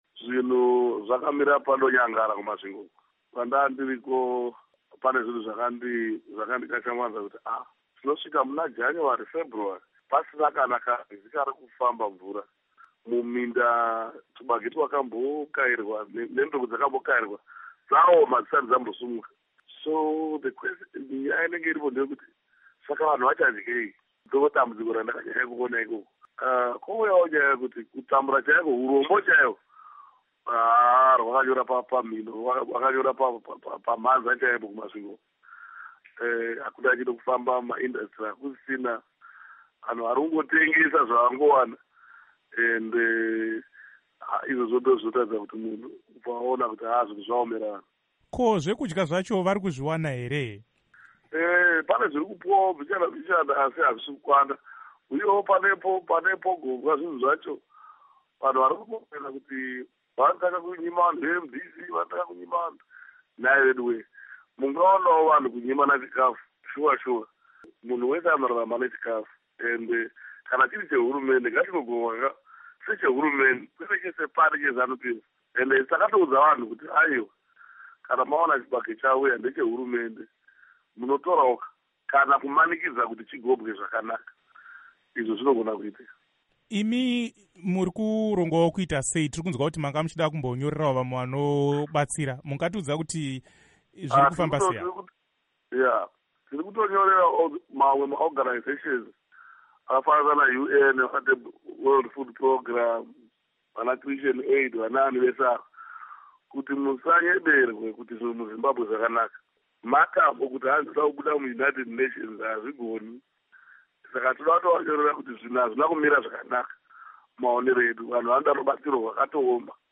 Vachitaura neStudio 7, VaTsvangirai vati vachanyora tsamba kumasangano anobatsira nechikafu kuti vasimbise nyaya yekuti vanhu vemuZimbabwe vanoda rubatsiro rwezvekudya.
Hurukuro naVaMorgan Tsvangirai